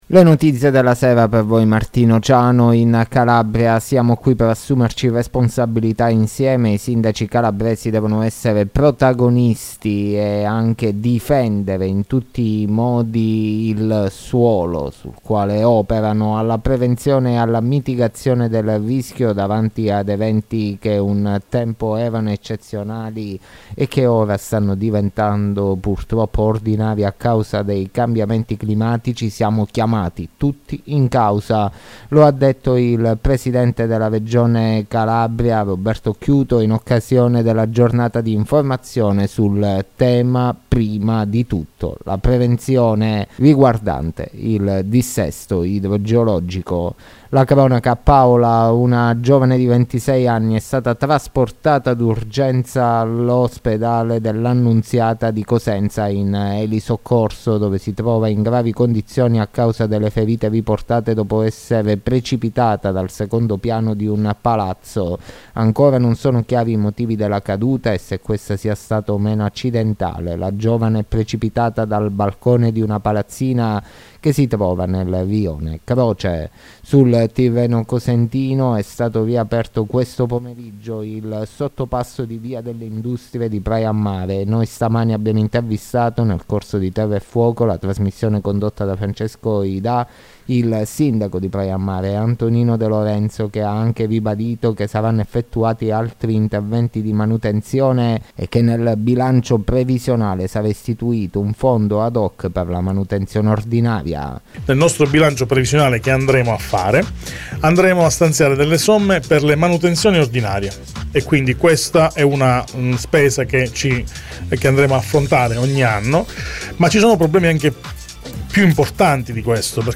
LE NOTIZIE DELLA SERA DI VENERDì 16 DICEMBRE 2022